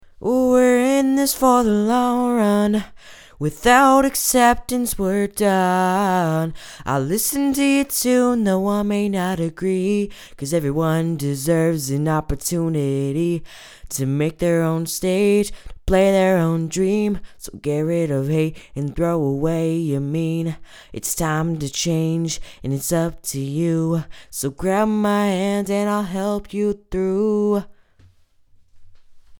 Need Better Mic Audio Quality; Advice?
I don't have very good quality at all.
Not the best vocals I guess now I'm just looking for advice on recording techniques.